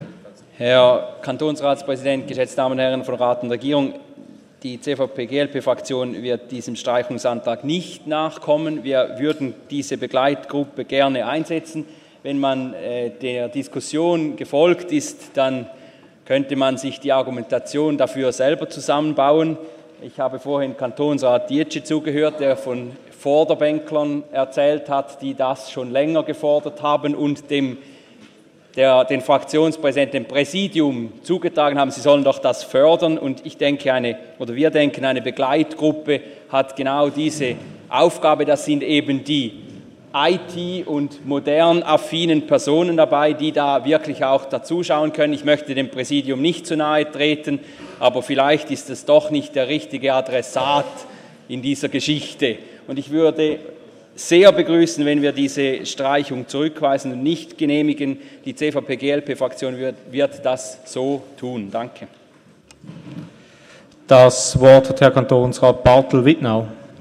Session des Kantonsrates vom 12. und 13. Juni 2017